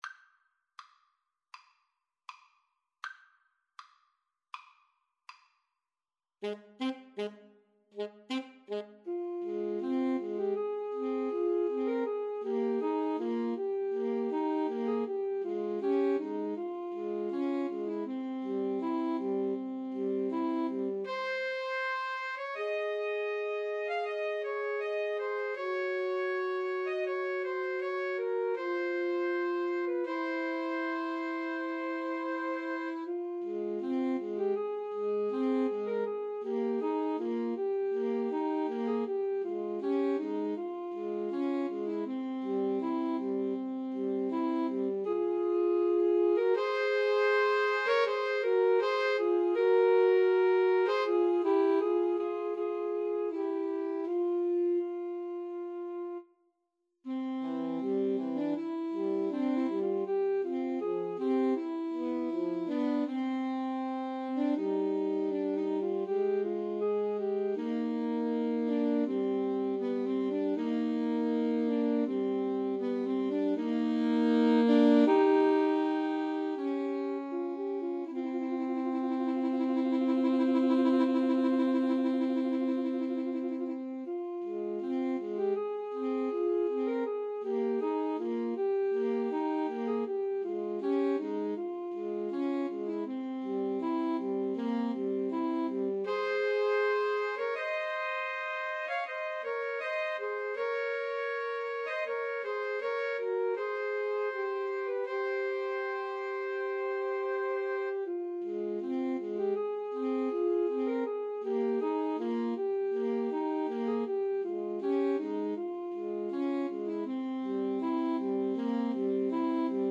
Alto Saxophone 1Alto Saxophone 2Tenor Saxophone
Andante
Classical (View more Classical 2-Altos-Tenor-Sax Music)